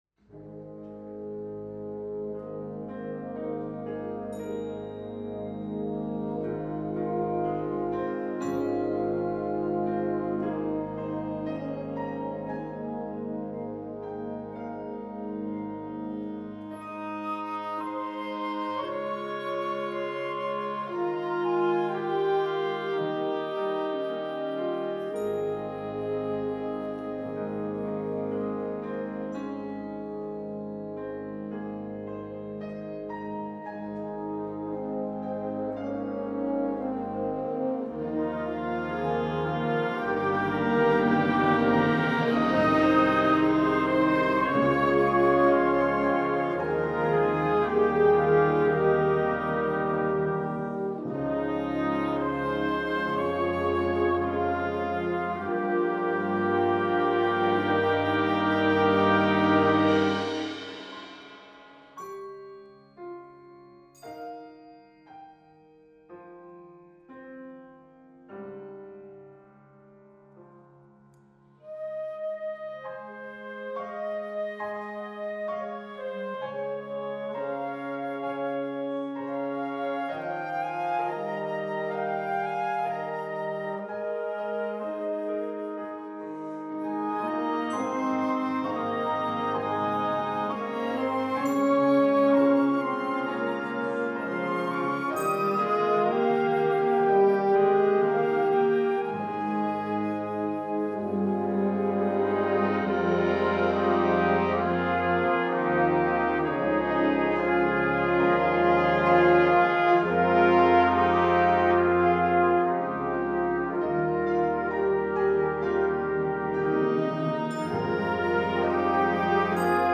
Instrumentation: concert band
concert, instructional, children